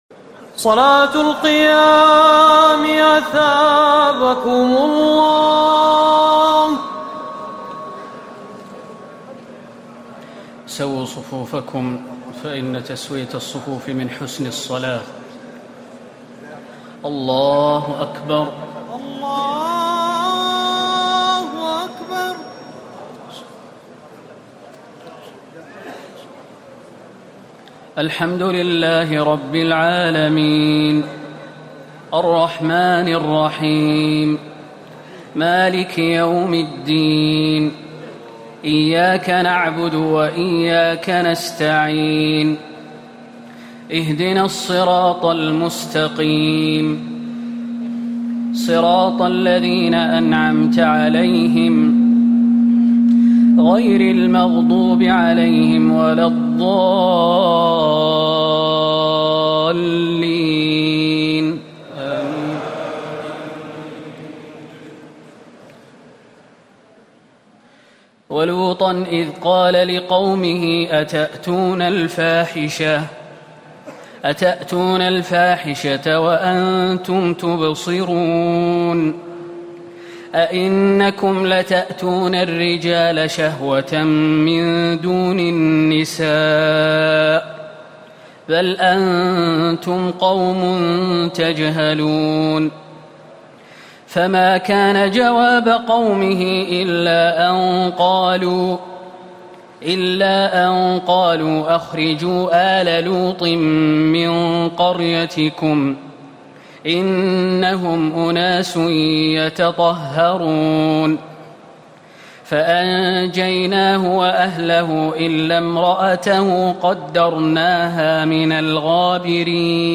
تراويح الليلة التاسعة عشر رمضان 1439هـ من سورتي النمل(54-93) و القصص(1-50) Taraweeh 19 st night Ramadan 1439H from Surah An-Naml and Al-Qasas > تراويح الحرم النبوي عام 1439 🕌 > التراويح - تلاوات الحرمين